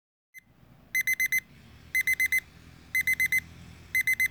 Tongenerator
• ▲ ▼ Hier die soundprobe.